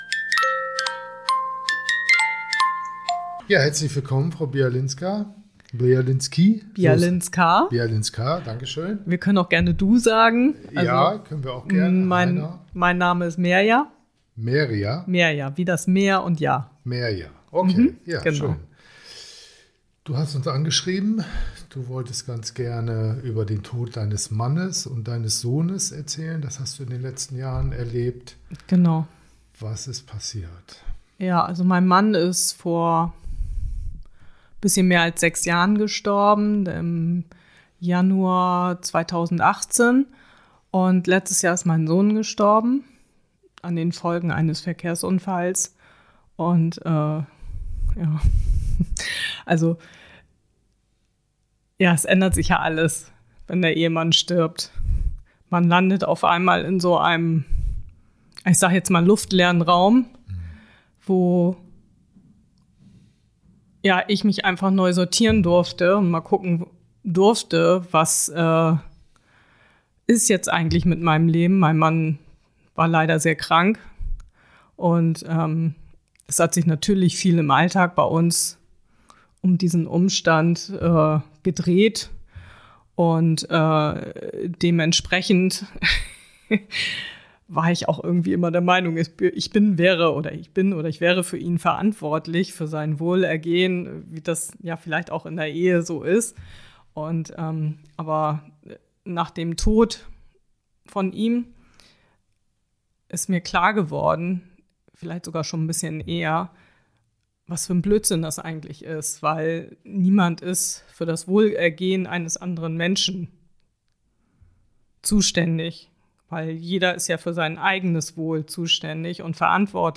Ich war eingeladen zu einem Interview bei TRAUERRAUM - der Podcast des trauerraum Bremen